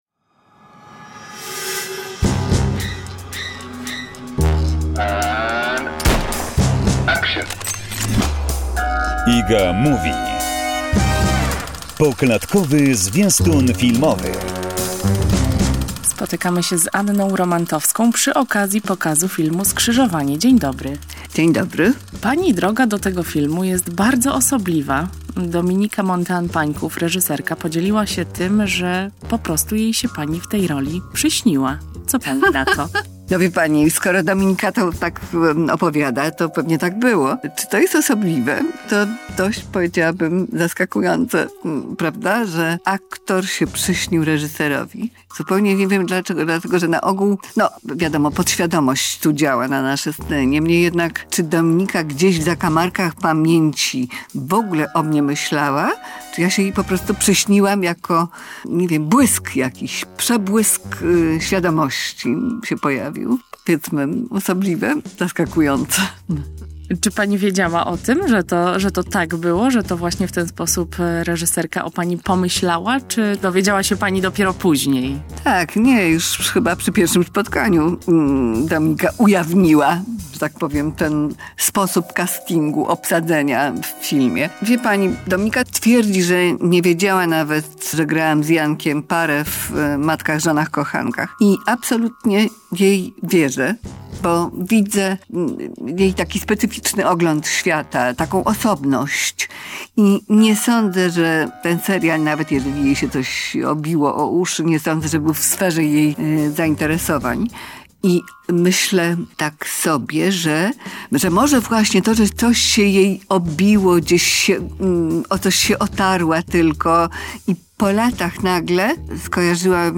rozmowa z Anną Romantowską